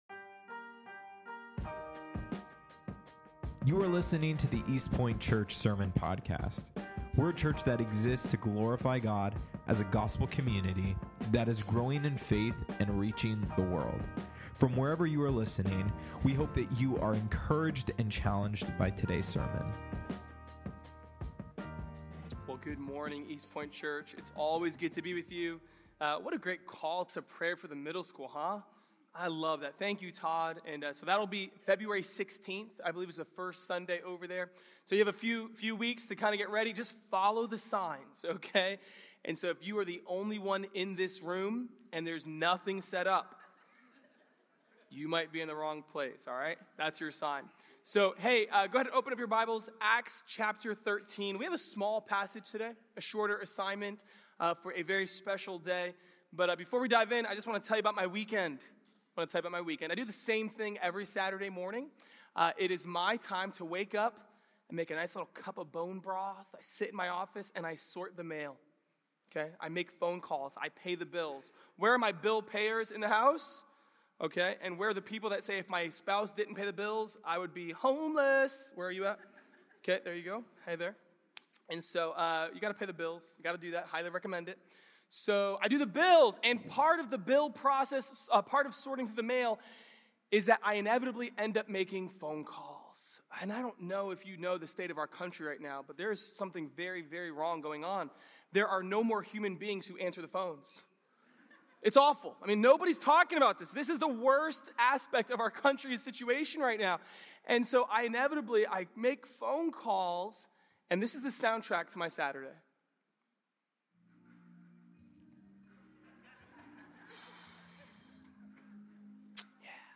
Sermon Series
Join us for a special Sunday as we open up the Scripture together and publicly ordain a new pastor!